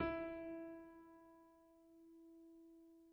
/piano/E4.mp3